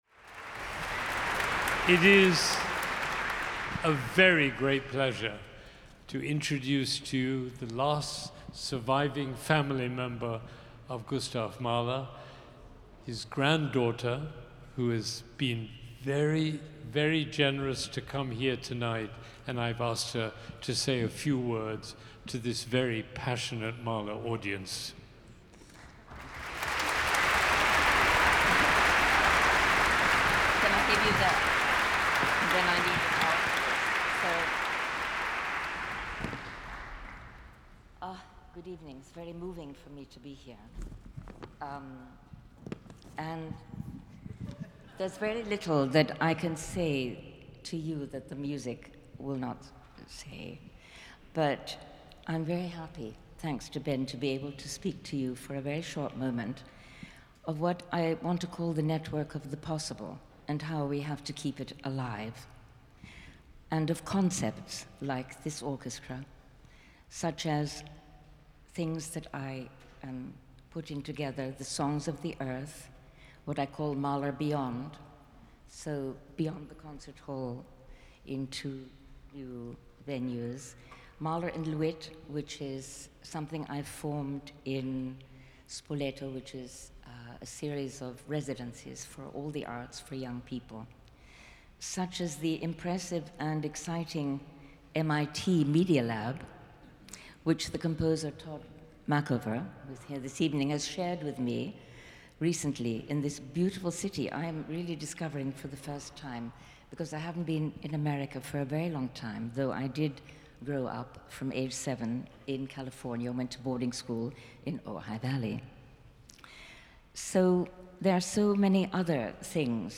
Recorded Live at Symphony Hall on April 18th, 2025